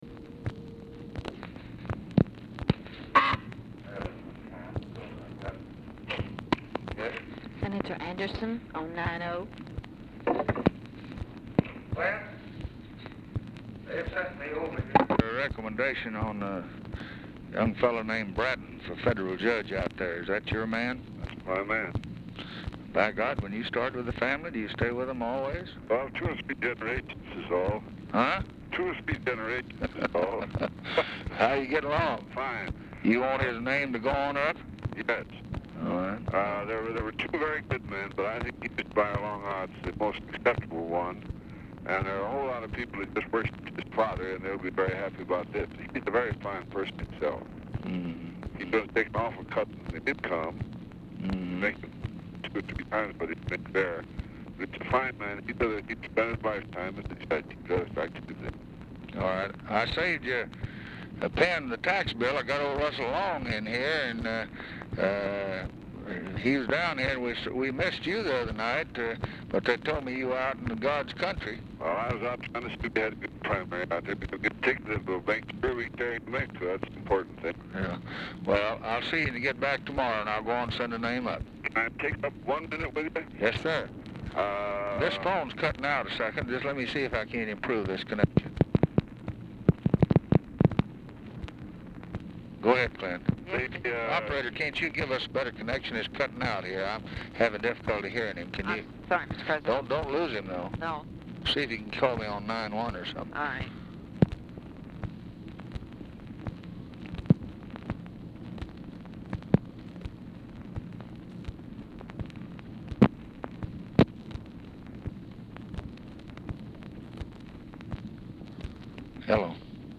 Telephone conversation # 2310, sound recording, LBJ and CLINTON ANDERSON, 3/2/1964, 12:46PM | Discover LBJ
POOR SOUND QUALITY; ANDERSON IS IN NEW MEXICO; LBJ ASKS OPERATOR FOR A BETTER CONNECTION, AND END OF CONVERSATION IS NOT RECORDED; LBJ TALKS WITH RUSSELL LONG WHO IS MEETING WITH HIM AT TIME OF CALL
Format Dictation belt
Other Speaker(s) TELEPHONE OPERATOR, OFFICE CONVERSATION, OFFICE SECRETARY